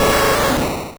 Cri de Tauros dans Pokémon Rouge et Bleu.